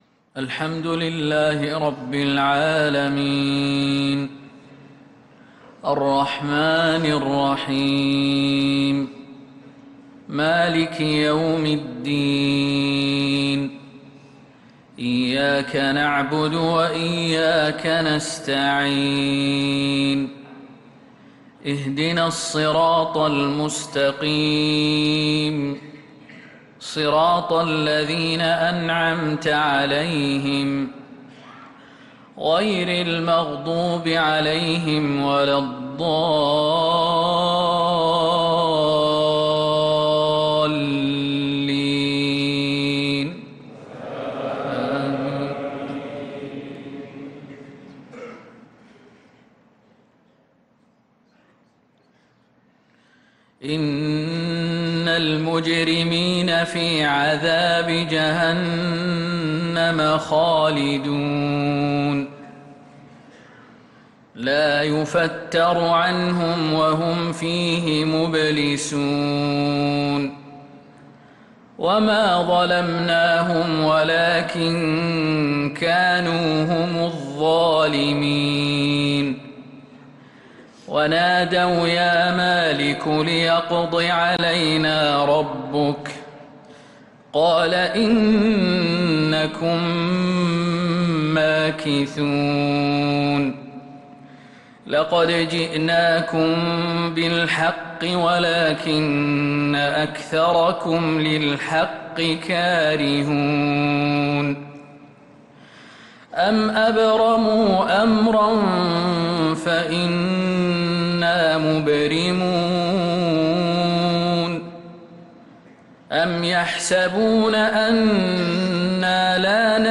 صلاة الفجر للقارئ خالد المهنا 16 شوال 1445 هـ
تِلَاوَات الْحَرَمَيْن .